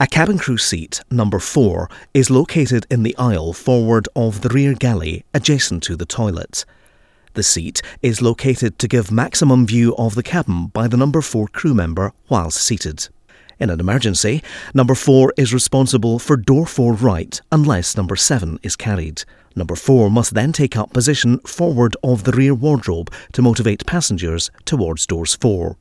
Mellow friendly characterful.
schottisch
Sprechprobe: eLearning (Muttersprache):
Authentic, Conversational, Convincing, Compelling, Natural,Positive,Punchy.